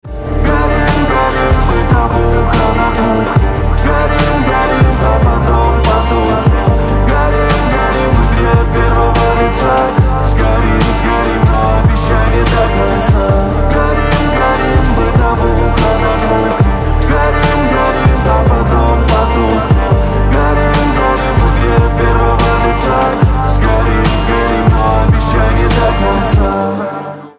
• Качество: 128, Stereo
мужской вокал
Хип-хоп
RnB